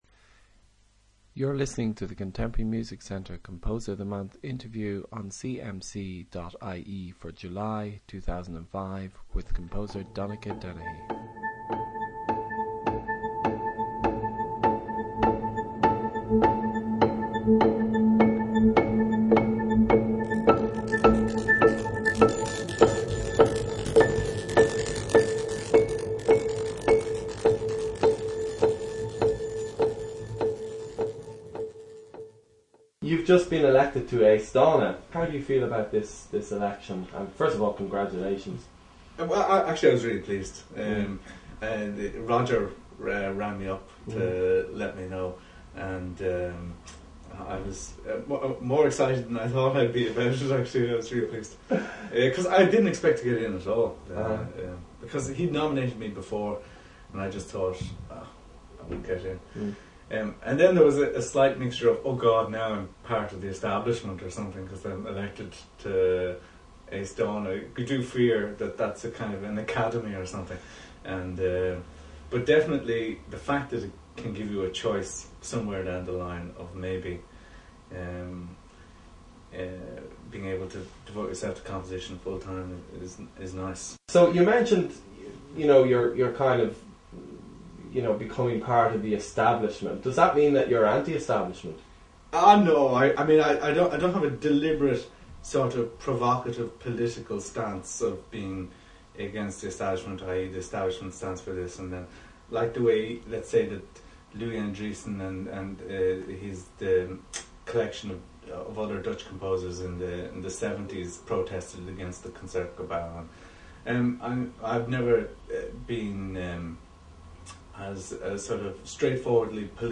An Interview with Donnacha Dennehy